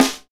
Index of /90_sSampleCDs/Northstar - Drumscapes Roland/KIT_Hip-Hop Kits/KIT_Rap Kit 3 x
SNR H H S08L.wav